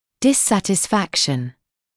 [ˌdɪsˌsætɪs’fækʃn][ˌдисˌсэтис’фэкшн]неудовлетворенность, недовольство